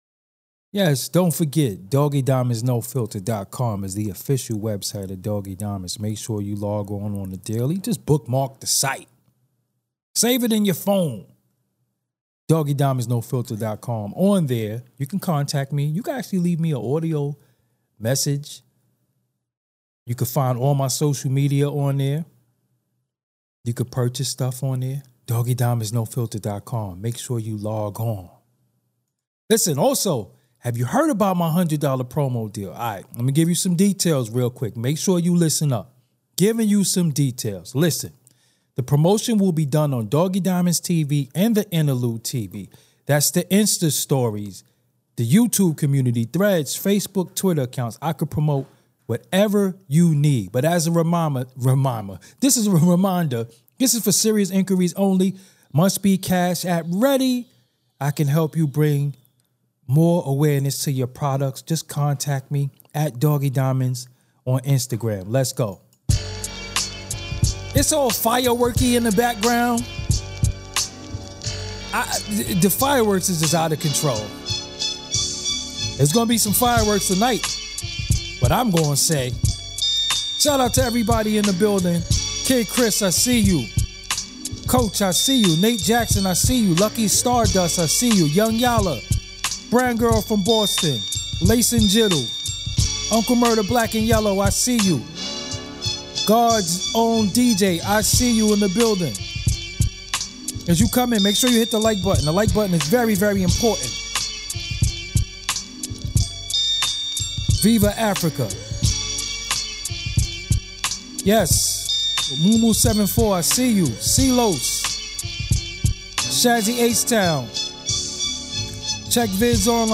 Tune in to gain unique insights and perspectives on relationships, gender dynamics, and more. Don't miss this candid and eye-opening conversation.